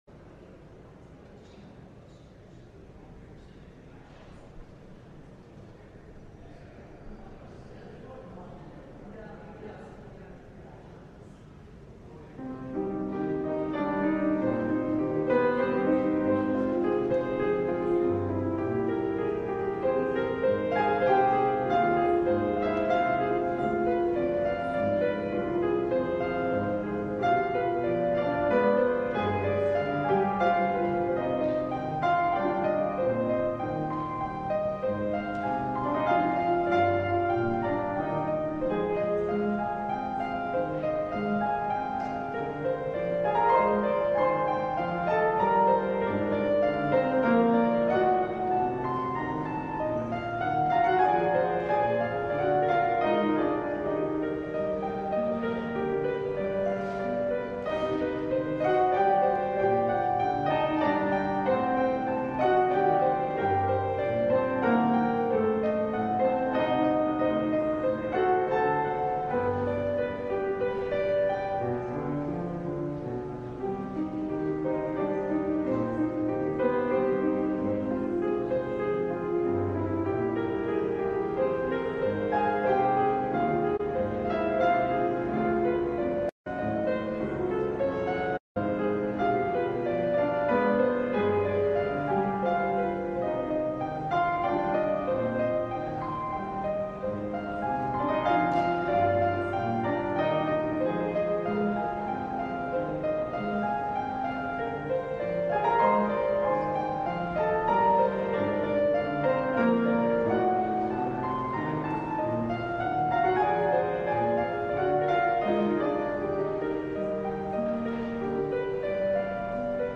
LIVE Morning Service - Cross Words: Sin & Slavery